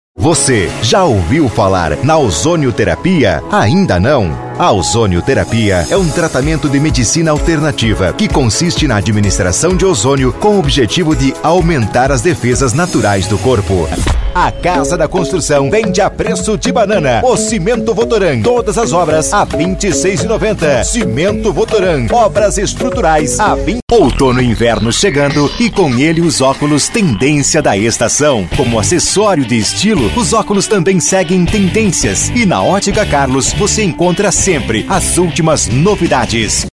Formatos: Spot ComercialVinhetasChamada de FestaVT ComercialAberturasURAEspera Telefônica Áudio Visual Post em Áudio
Estilo(s): PadrãoAnimada Varejo